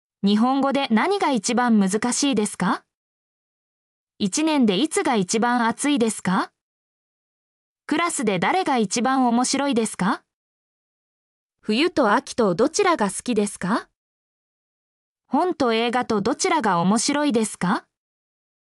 mp3-output-ttsfreedotcom-2_PFBDwmnS.mp3